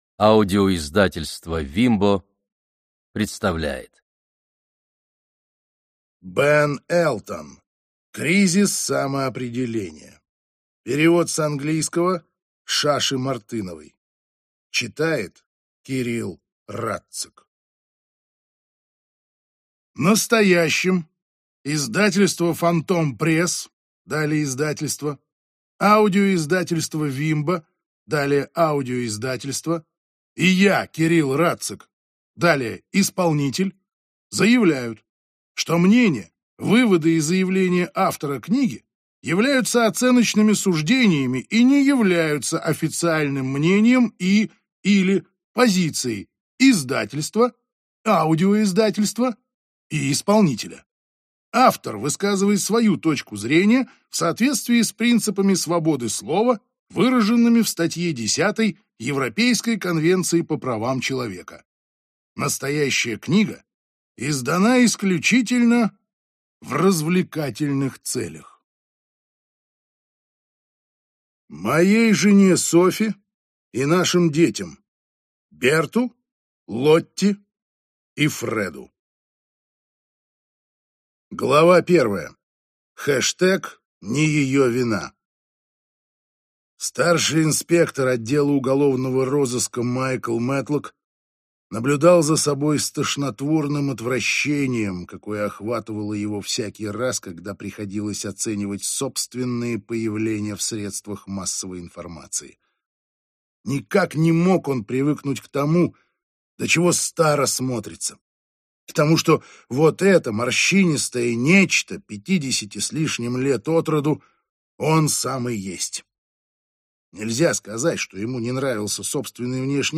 Аудиокнига Кризис самоопределения | Библиотека аудиокниг